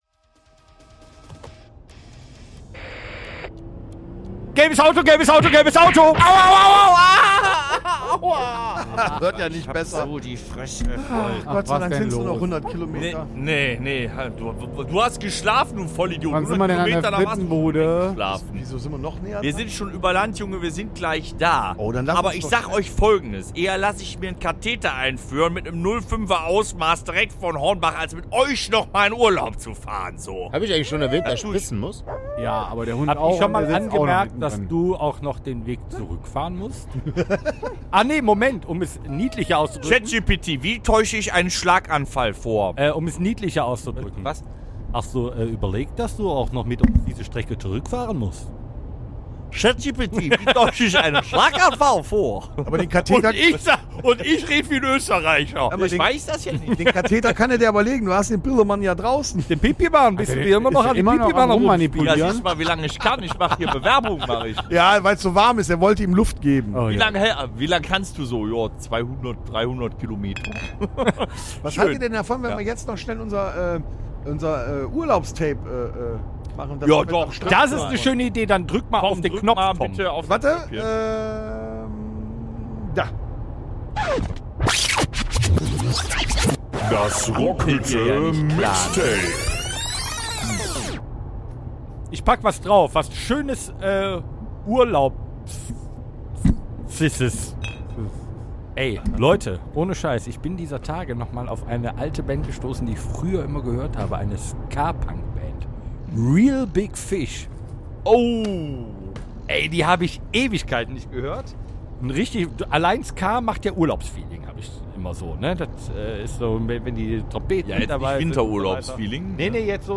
#251 | Küstengeflügel mit Gewaltpotential (Hörspiel Special) ~ Rockhütte Podcast